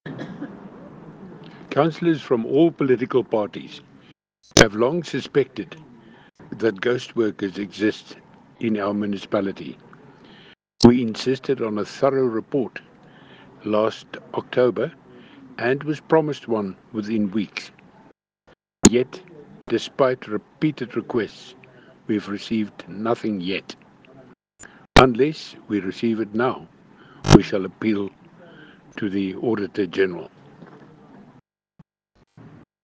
Afrikaans soundbites by Cllr Arnold Schoonwinkel and